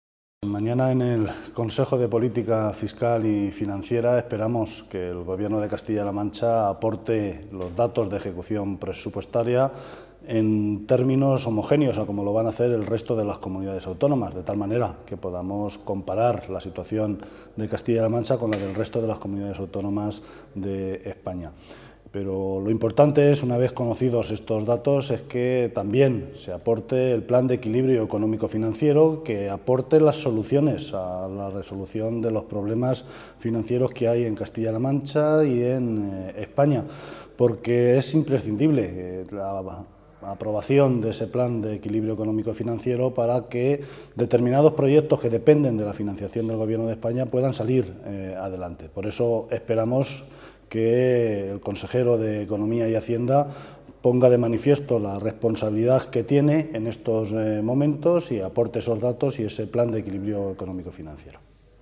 El portavoz del Grupo Socialista en las Cortes de Castilla-La Mancha, José Luis Martínez Guijarro, pidió hoy al Gobierno regional que aporte los datos de ejecución presupuestaria “en términos homogéneos, al igual que los van a hacer el resto de las Comunidades Autónomas”, en el Consejo de Política Fiscal y Financiera (CPFF) que tendrá lugar mañana miércoles.
Cortes de audio de la rueda de prensa